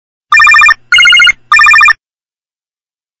Звуки дверного звонка
Тройной звонок звук